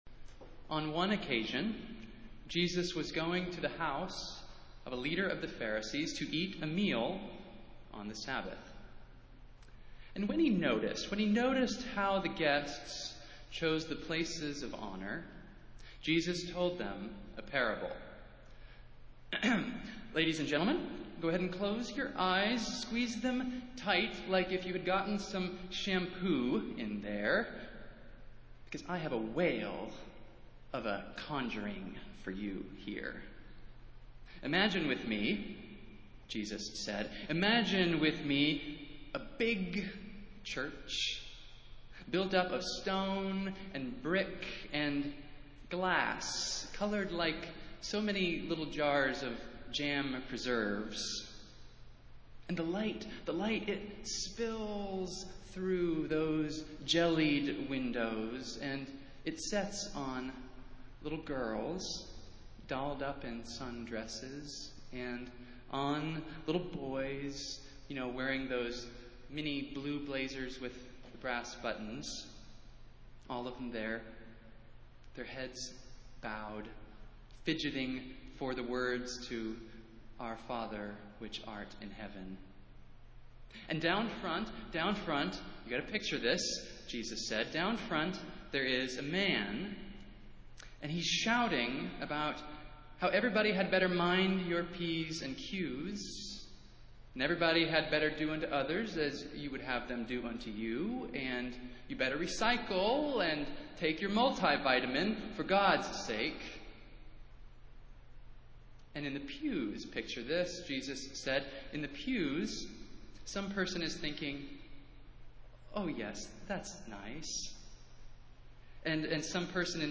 Festival Worship - Fifteenth Sunday after Pentecost